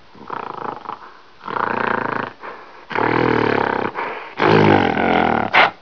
c_goril_bat2.wav